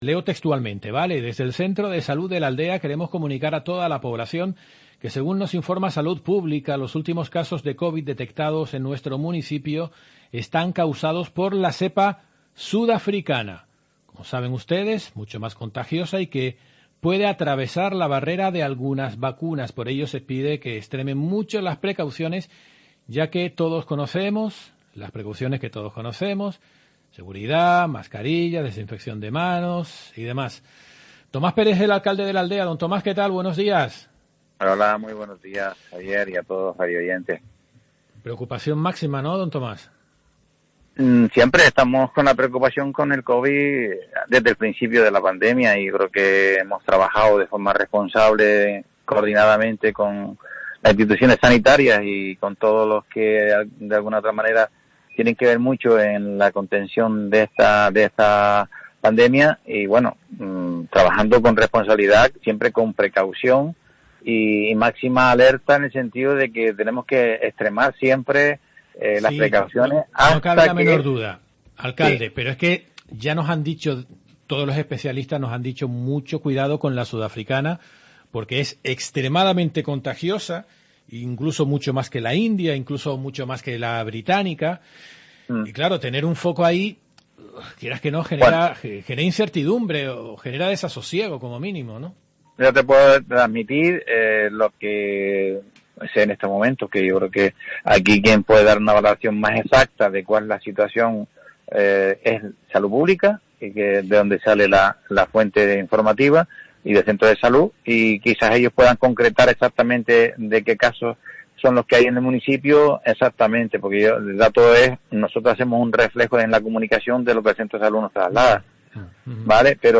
Tomás Pérez, alcalde de La Aldea de San Nicolás
Tomás Pérez, alcalde de La Aldea, ha asegurado en los micrófonos de COPE Gran Canaria que, continúan con la alerta por los posibles contagios de coronavirus.